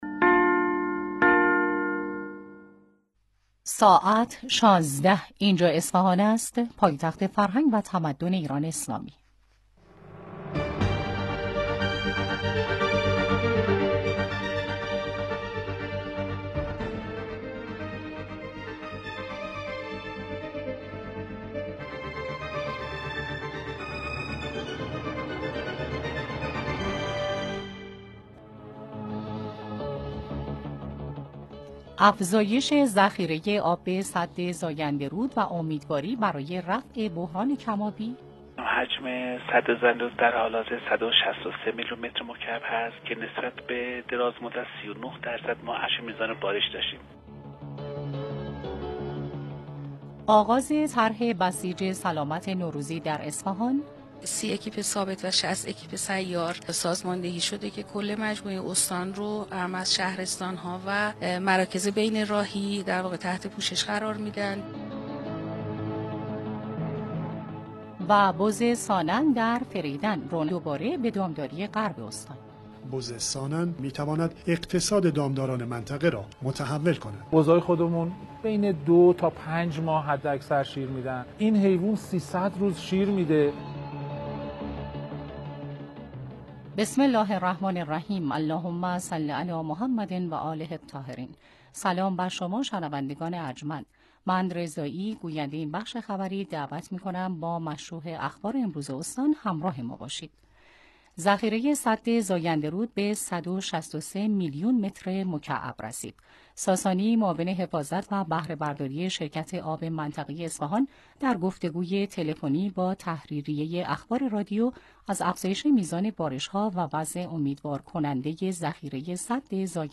مهمترین رویدادهای استان اصفهان را از بخش خبری 16 رادیوی مرکز اصفهان بشنوید.